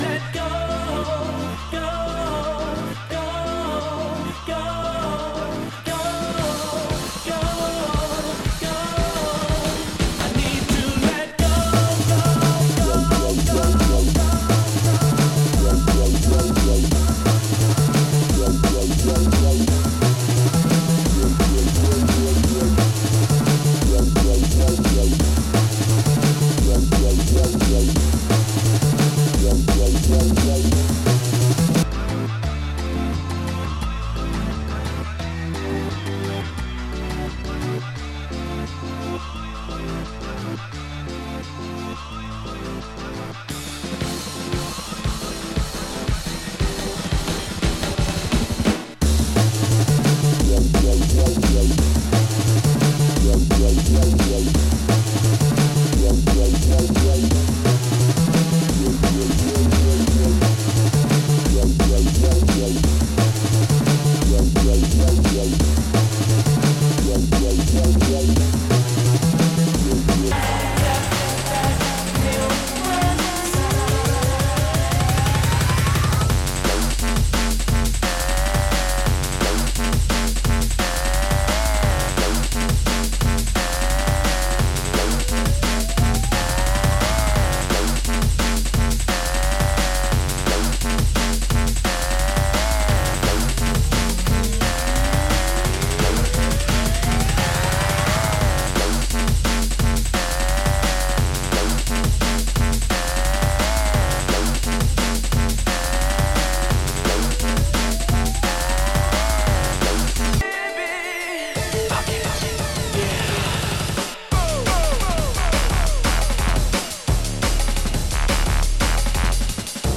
Vocal Mix
Dub Mix